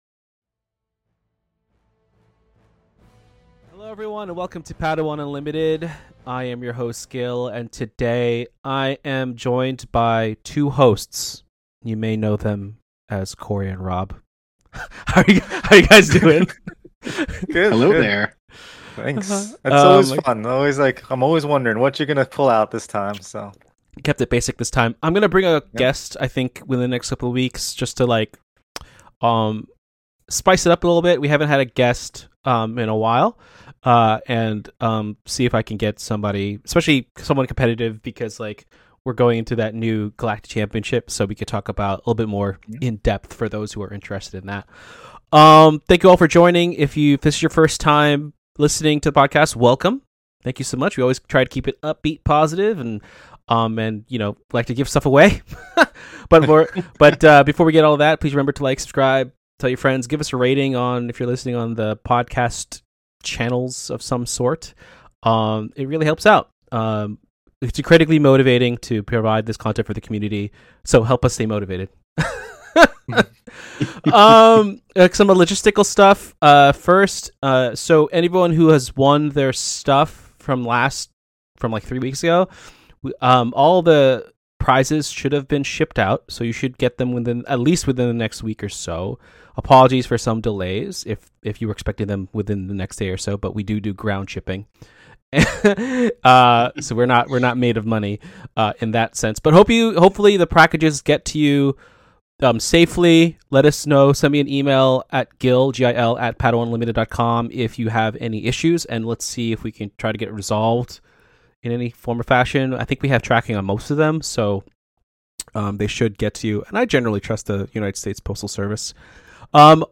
Always upbeat and positive, Padawan Unlimited is a a Star Wars Unlimited (SWU) Trading Card Game podcast dedicated to learning about and building the Star Wars Unlimited community, one episode, one post, one person at a time.